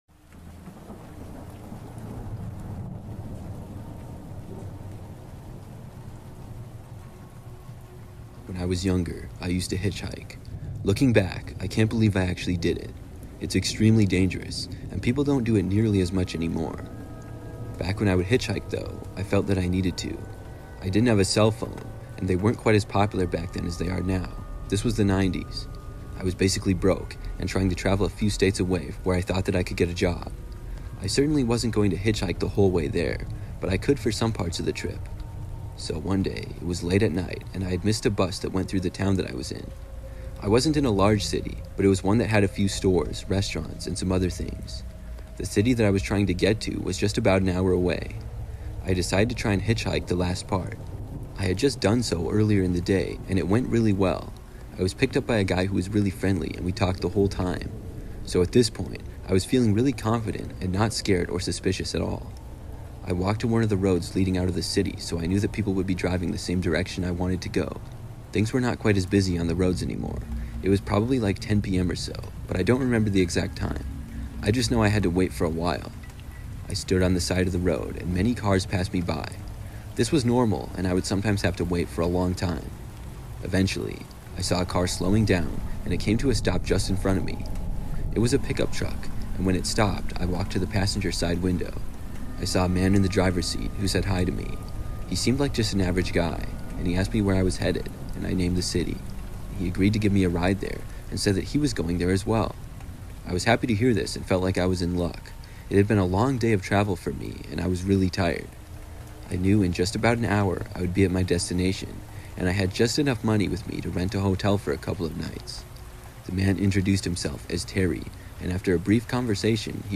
True McDonald's Horror Stories (With Rain Sounds) That Will Ruin Your Visits Forever